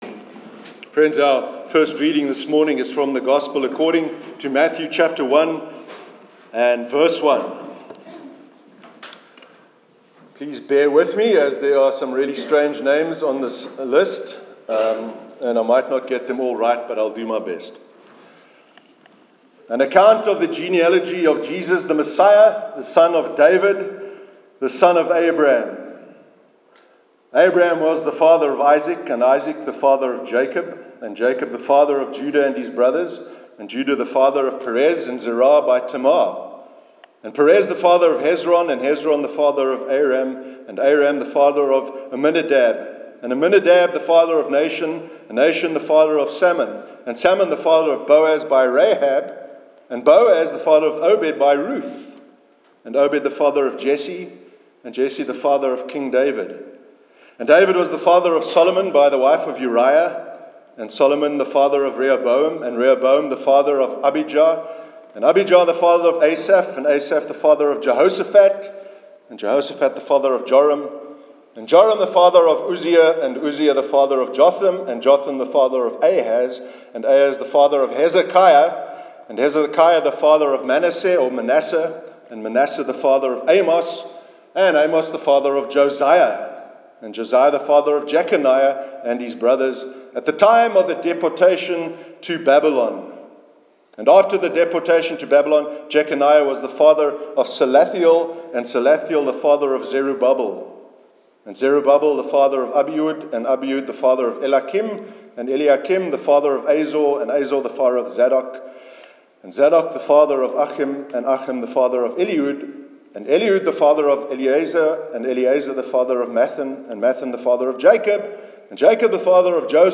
Sermon: Third Sunday of Advent